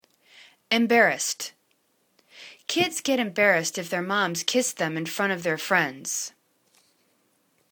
em.bar.rassed     /im'barəst/    adj